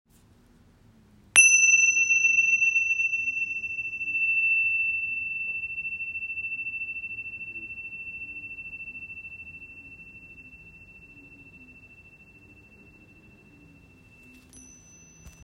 Tingsha Cymbals – 7cm
Tingsha are small, traditional Tibetan cymbals, handcrafted and joined by a leather strap or cord. When struck together, they produce a clear, high-pitched tone that is both bright and long-lasting.